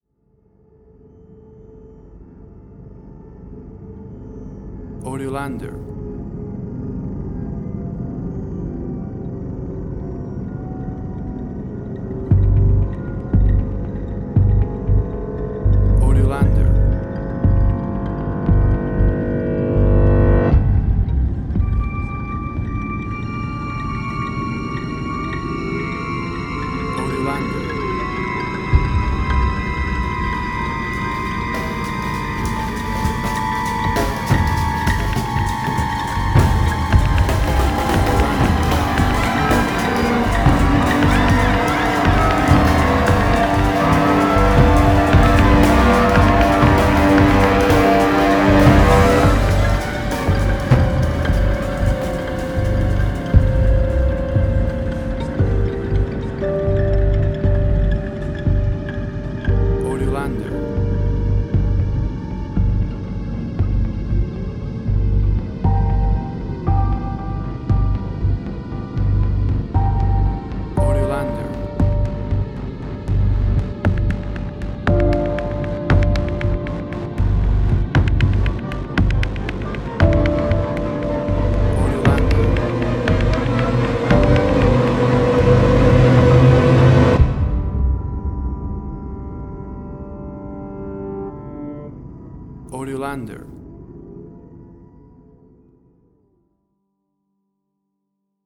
Modern Science Fiction Film, Similar Tron, Legacy Oblivion.
Tempo (BPM): 59